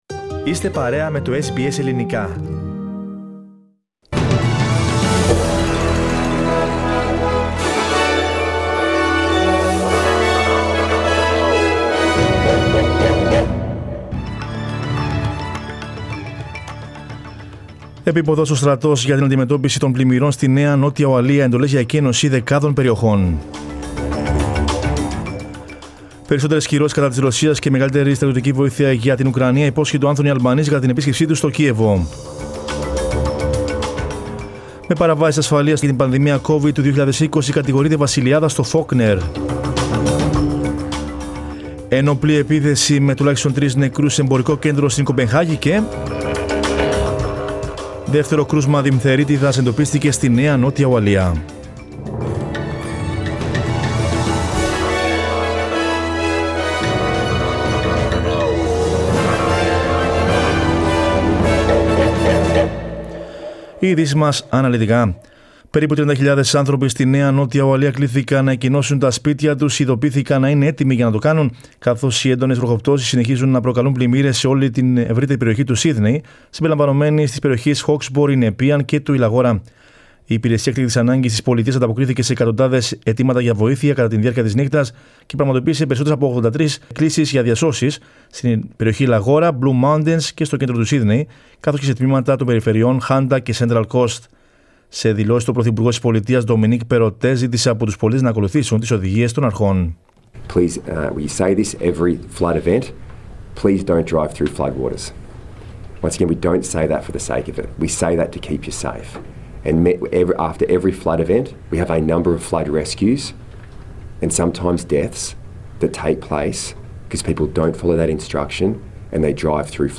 News in Greek from Australia, Greece, Cyprus and the world is the news bulletin of Monday 4 July 2022.